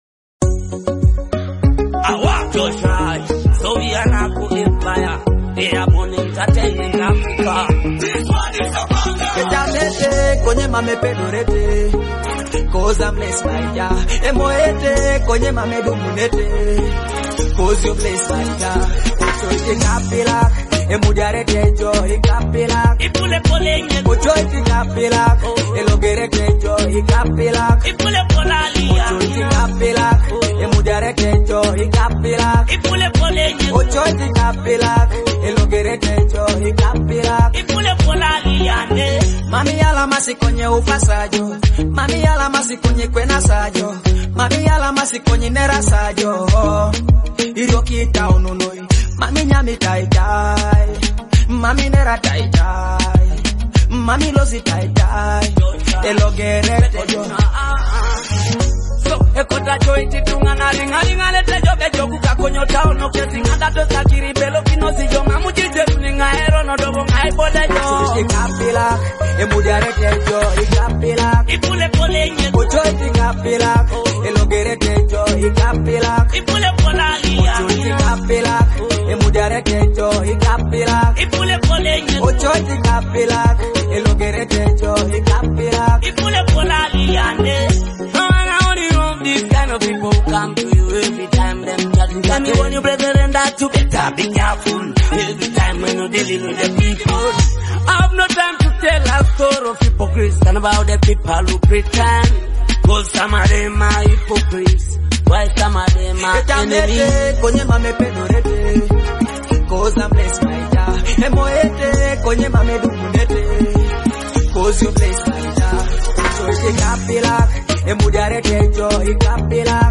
With its infectious hooks and powerful vocal delivery